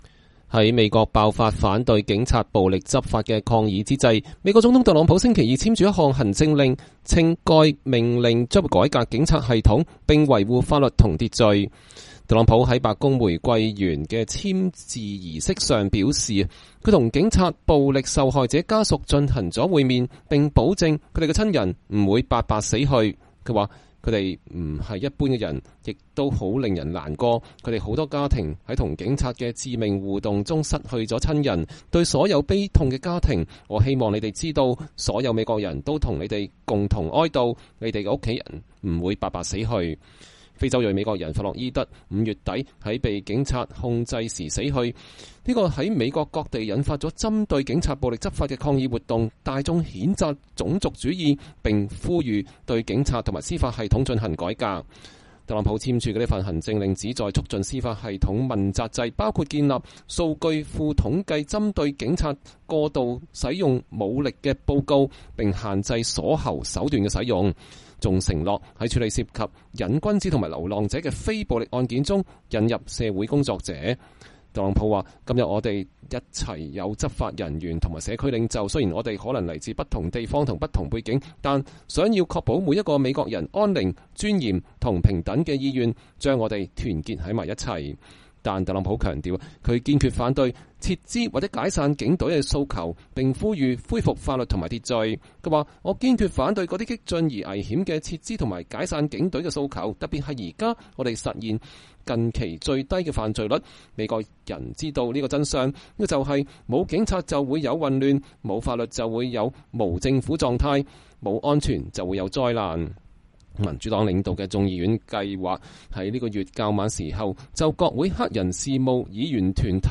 在華盛頓的白宮玫瑰園，美國總統特朗普簽署了一項關於警察改革的行政命令。（20年6月16日）。